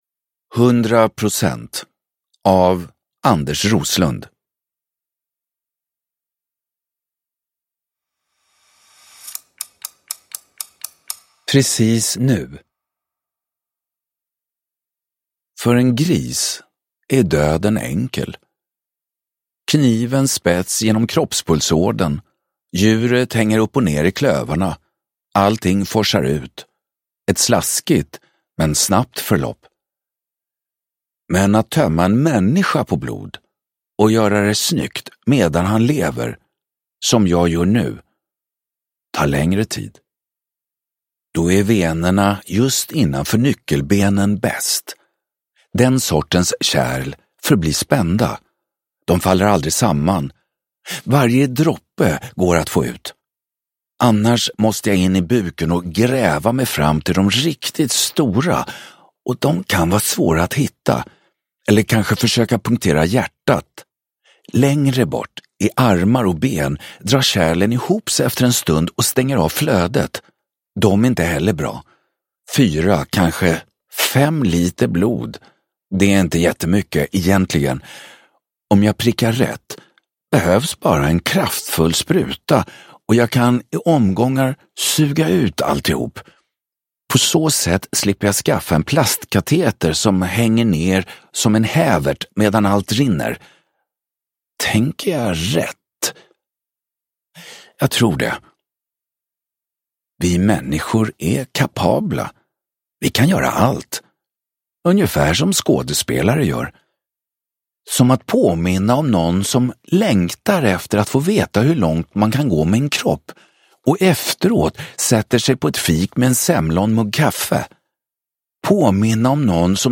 100 procent – Ljudbok – Laddas ner
Uppläsare: Thomas Hanzon